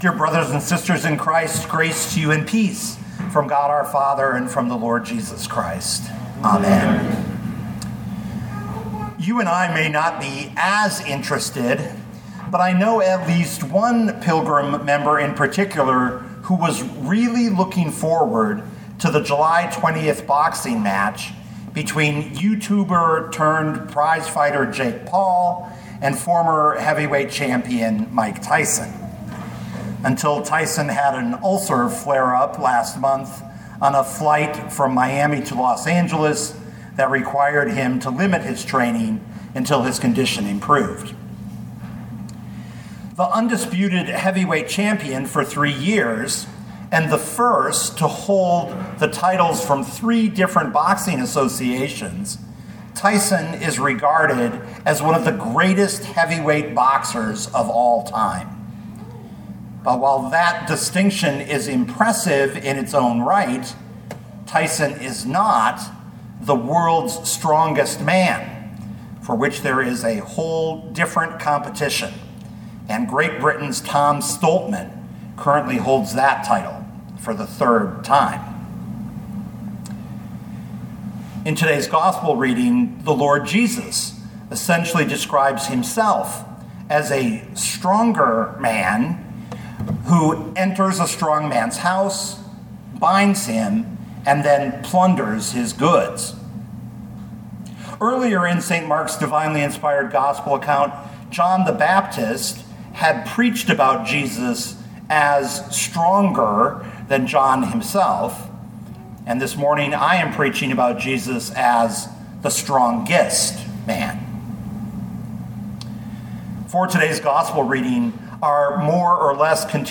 2024 Mark 3:20-35 Listen to the sermon with the player below, or, download the audio.